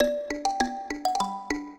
mbira
minuet12-2.wav